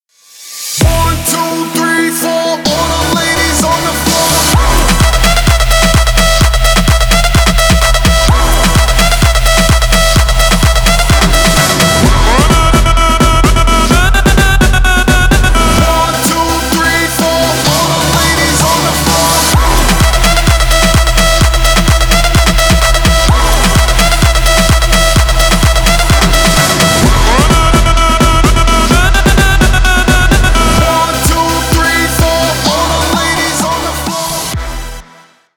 Танцевальные
громкие